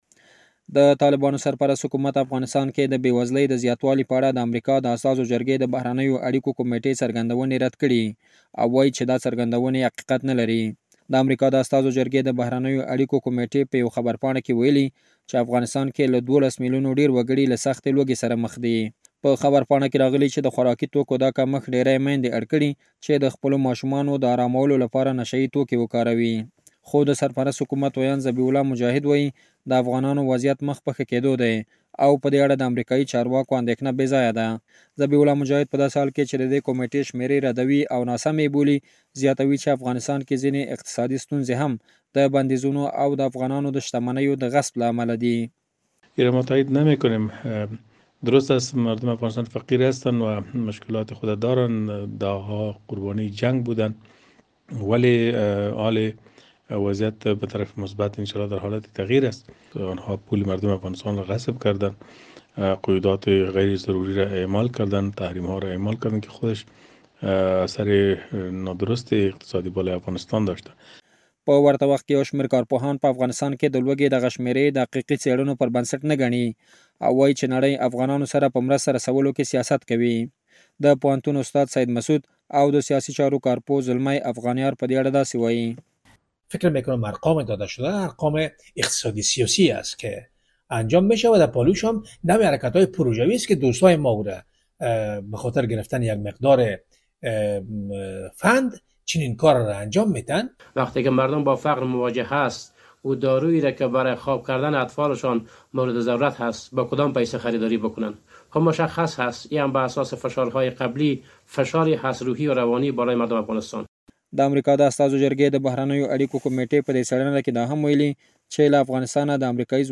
زمونږ خبریال راپور راکړی د طالبانو سرپرست حکومت افغانستان کې د بې‌وزلي د زیاتوالي په اړه د امریکا استازو جرګې د بهرنیو اړیکو کمیټې څرګندونې رد کړې او وايي،...